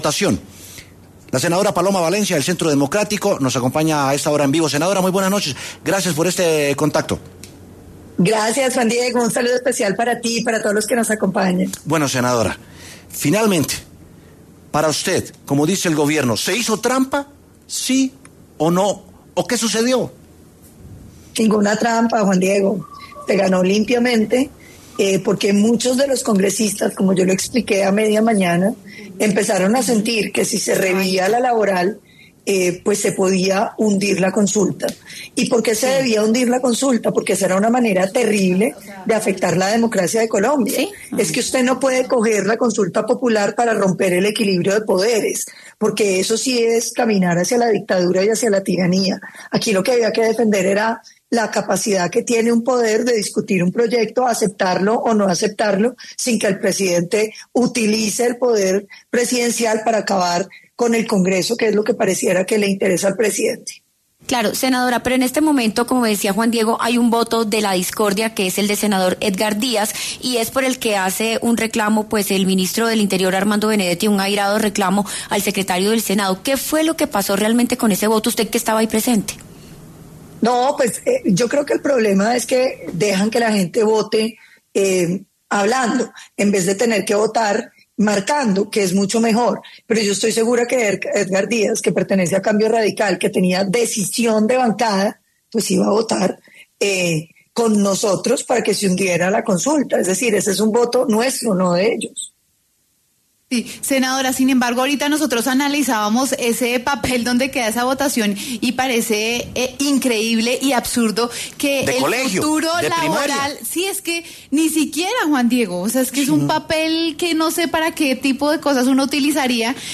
La senadora de la oposición, Paloma Valencia, conversó con W Sin Carreta sobre el hundimiento de la consulta popular propuesta por el Gobierno Petro.
Para hablar sobre el tema, la senadora opositora Paloma Valencia, del Centro Democrático, pasó por los micrófonos de W Sin Carreta.